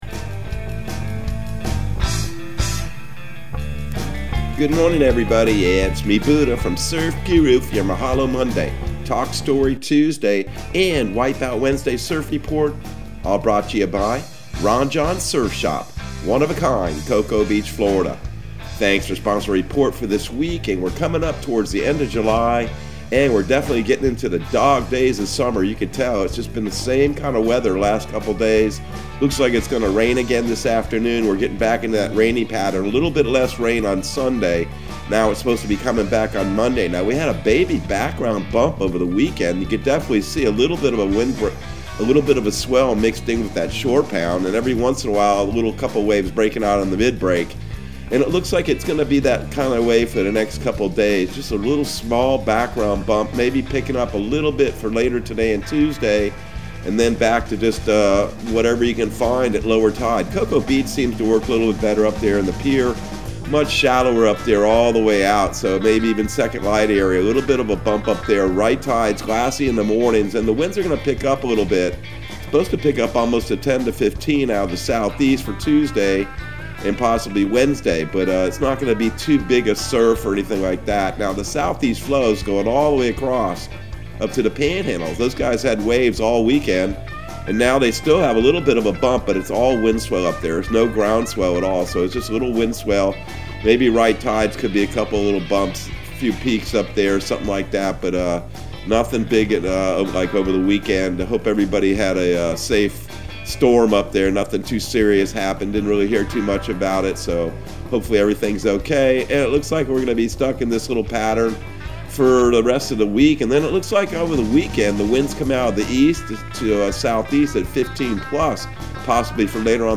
Surf Guru Surf Report and Forecast 06/21/2021 Audio surf report and surf forecast on June 21 for Central Florida and the Southeast.